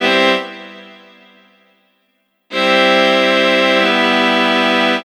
Track 13 - Strings 02.wav